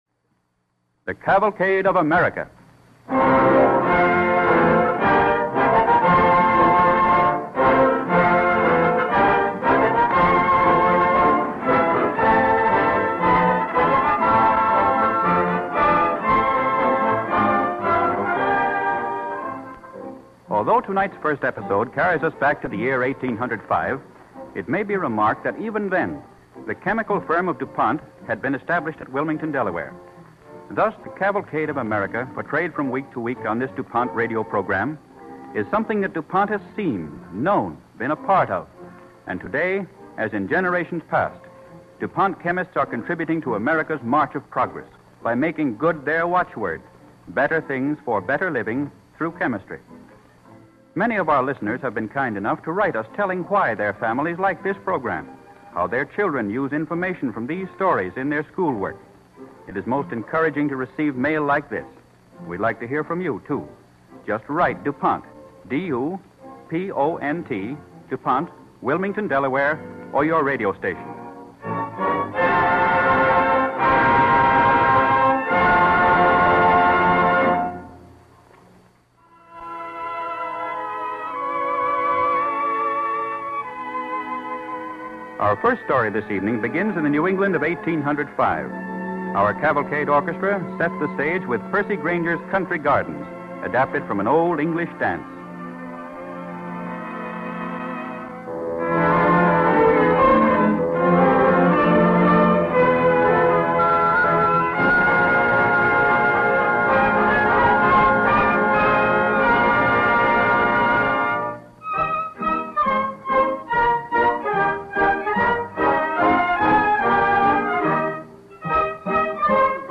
Listen to and download the Cavalcade of America Radio Program